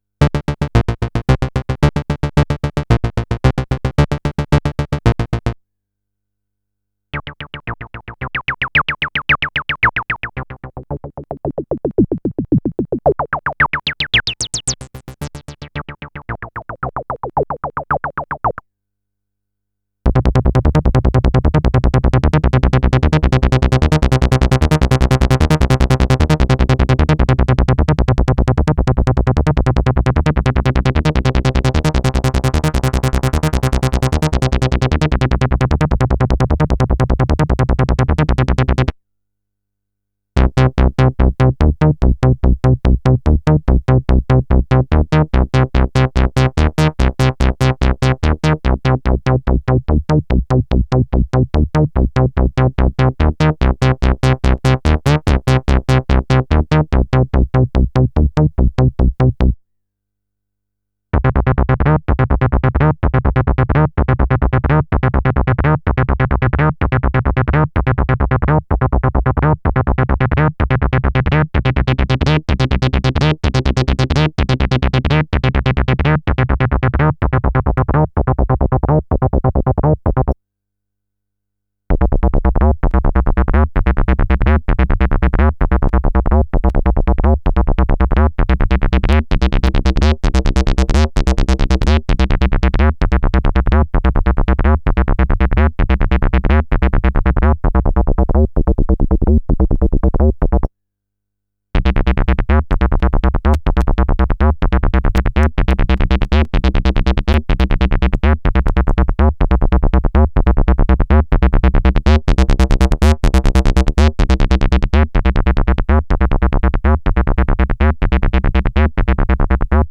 40 MC 202.wav